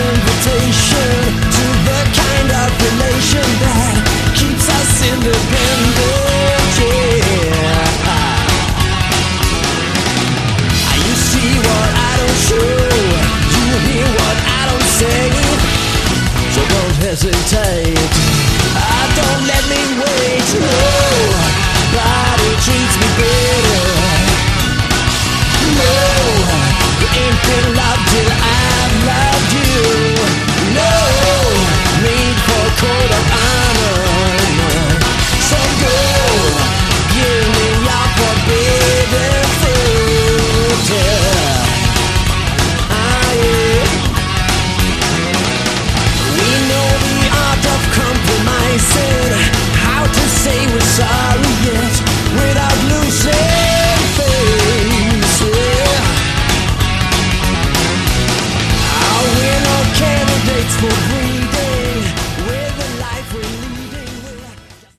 Category: Melodic Rock
keyboards, Hammond Organ